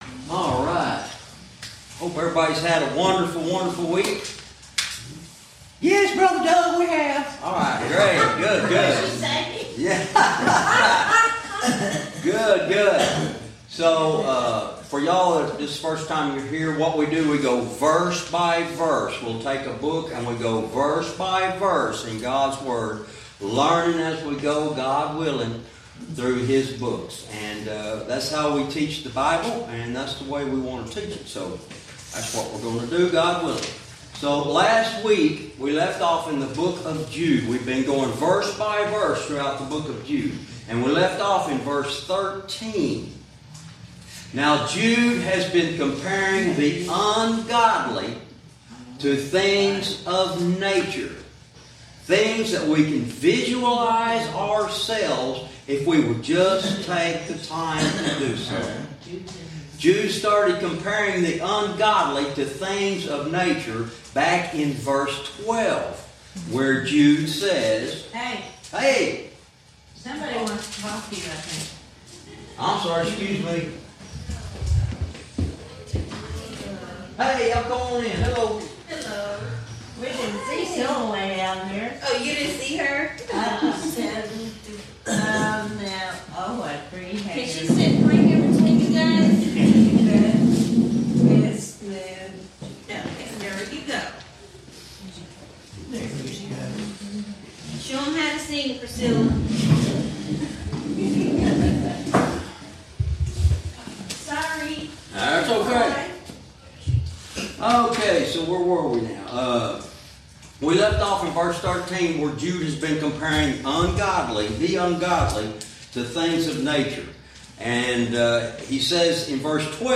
Verse by verse teaching - Jude Lesson 56 Verse 13